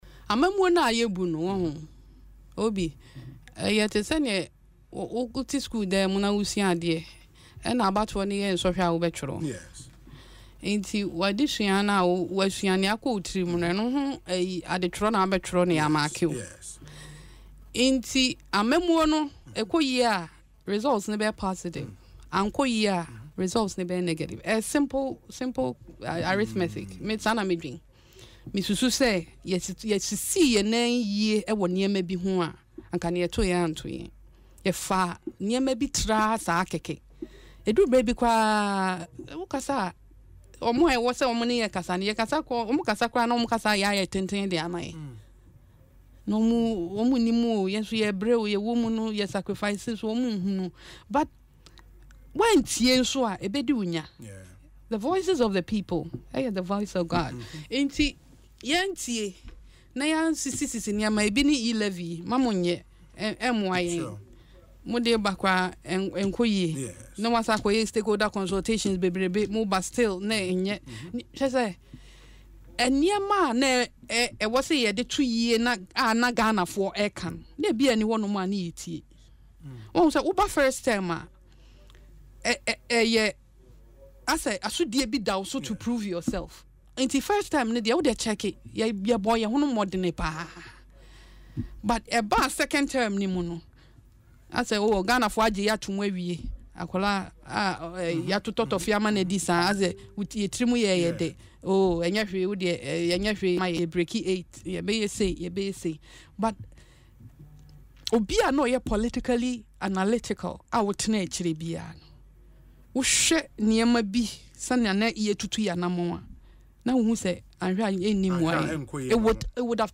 Speaking on Asempa FM’s Ekosii Sen, Adwoa Safo noted that at some point, the NPP disregarded the concerns of the people, making it evident to politically analytical minds that victory was unlikely.